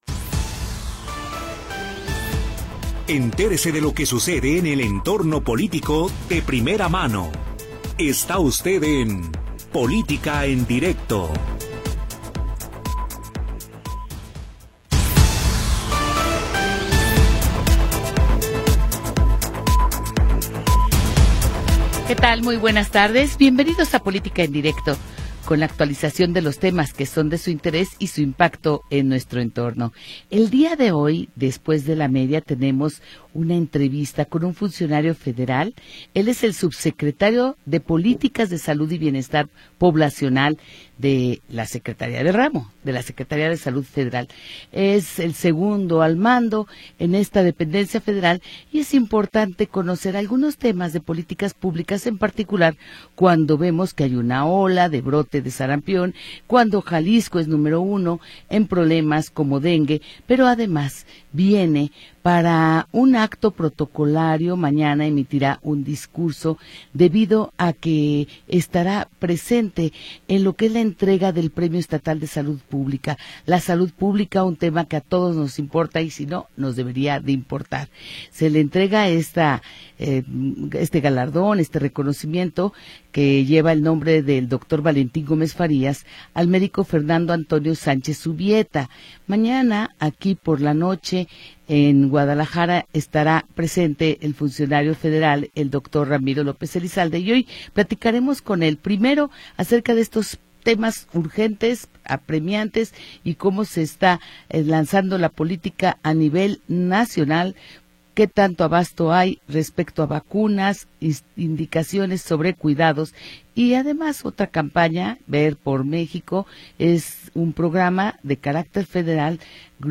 Programa transmitido el 17 de Julio de 2025.